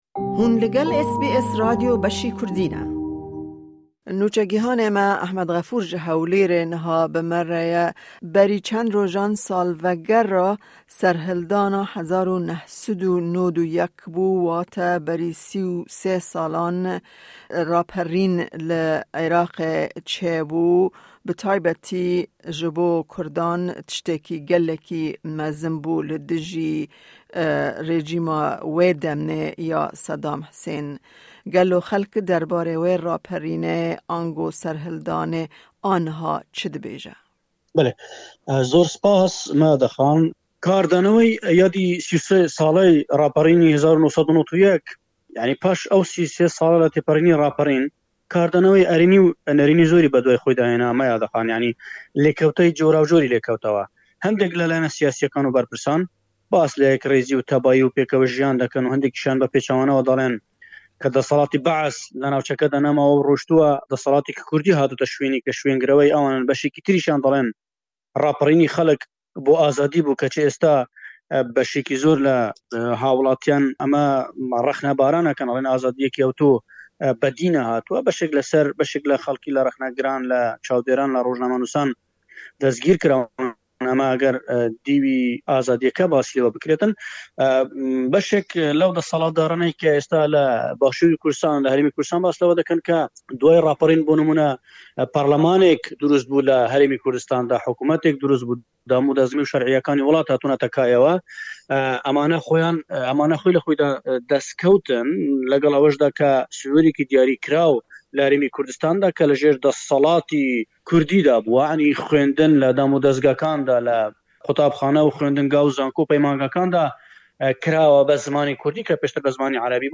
Xelk bi cesaret û îradeya xwe bajar ji bindestiya desthelatdariya wê demê ya Sedam Husên rizgar kir û li seranserê başûrê Kurdistanê îlhama serhildanên bi vî rengî pêk hatin. Nûçegîhan